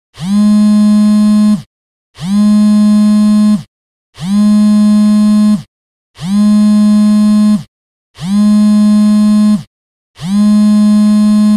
Categoria Telefone